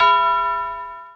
Sound (GlockenSound).wav